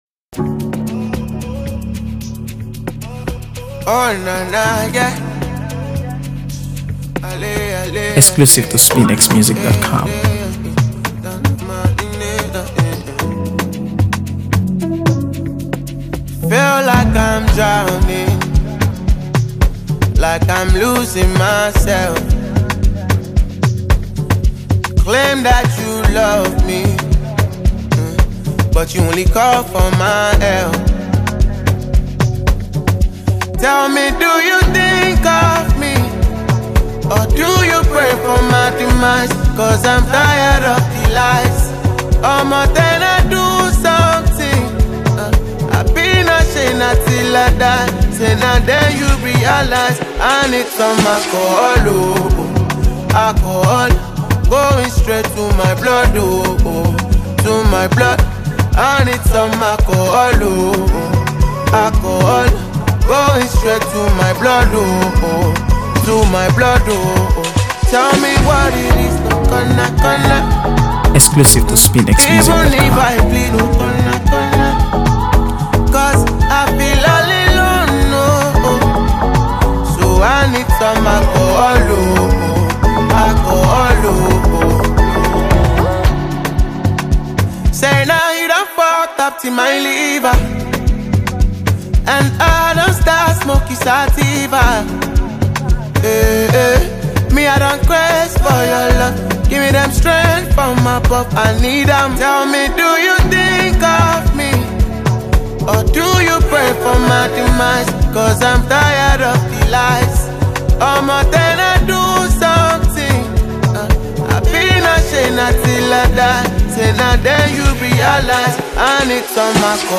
AfroBeats | AfroBeats songs
is a moody, rhythm-driven record
With his signature blend of Afrobeats and R&B